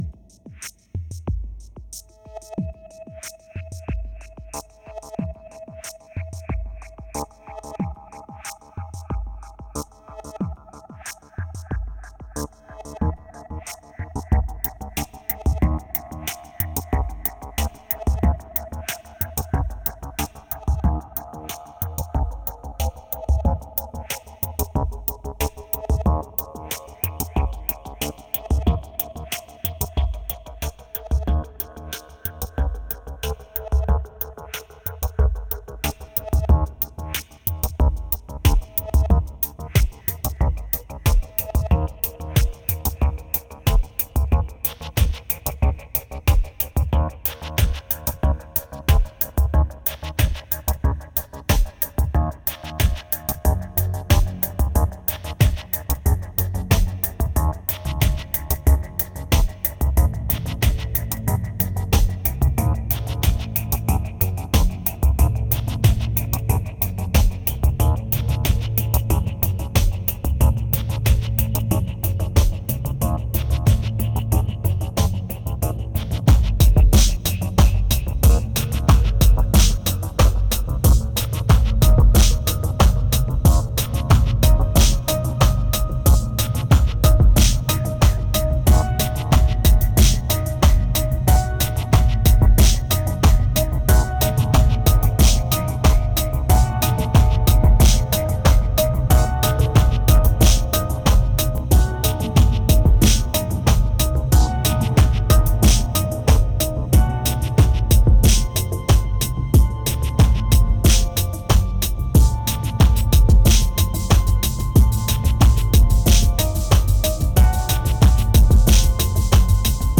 2144📈 - 37%🤔 - 92BPM🔊 - 2012-10-06📅 - -17🌟